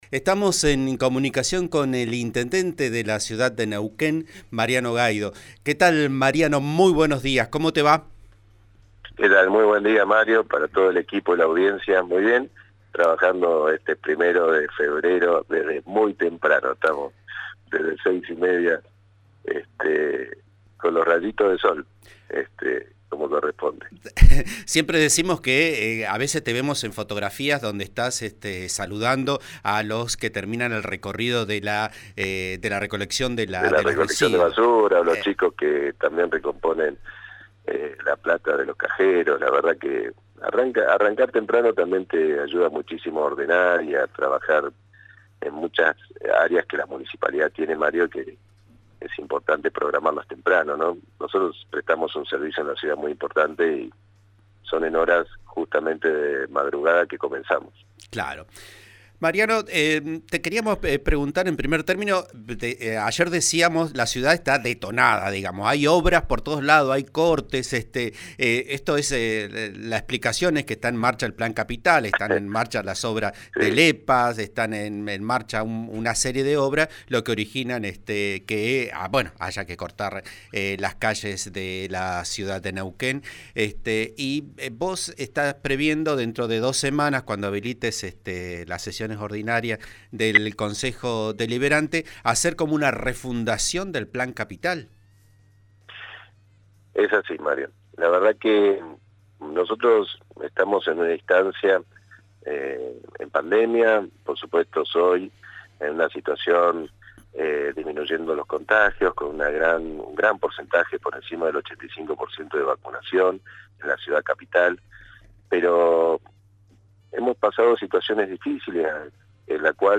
Vamos a destinar 700 millones de pesos del superávit de la municipalidad para esto que va a suceder este año, al finalizar el 2022 los vamos a entregar», afirmó esta mañana en declaraciones a Vos A Diario, RN Radio 89.3.